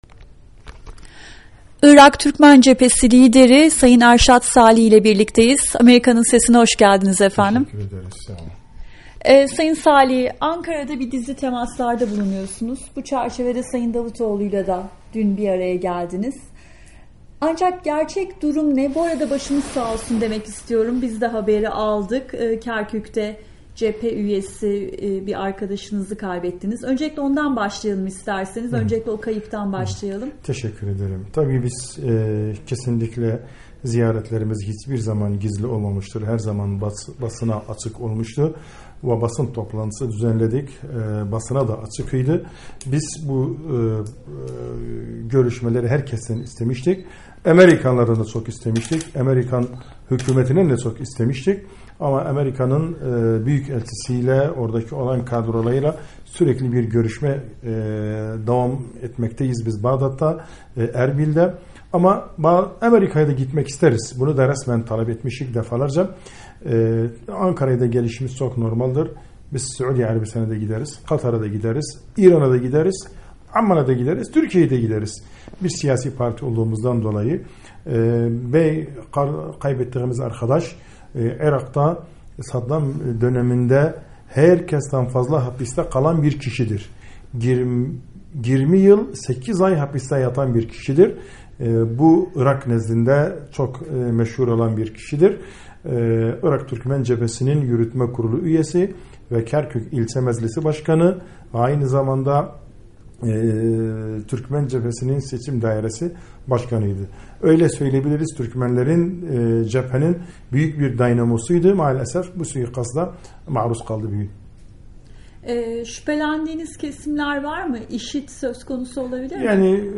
Irak Türkmen Cephesi Lideri Erşat Salihi ile Söyleşi